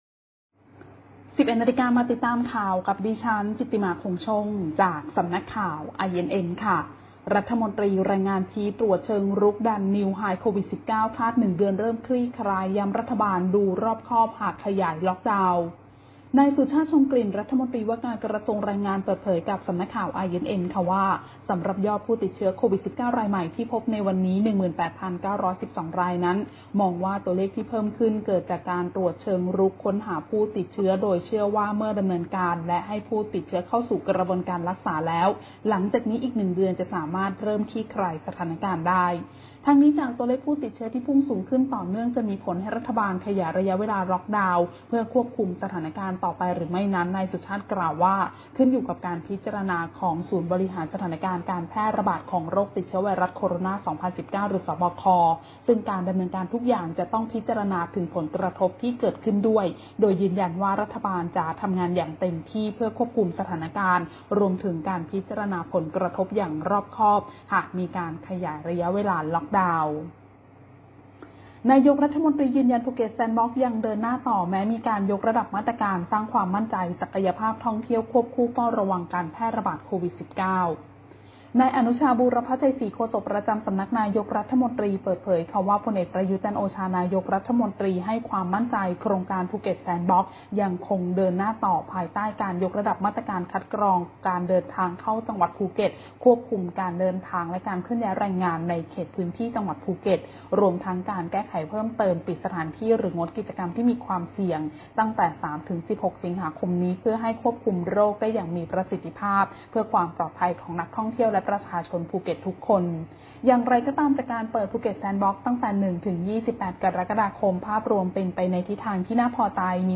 คลิปข่าวต้นชั่วโมง
ข่าวต้นชั่วโมง 11.00 น.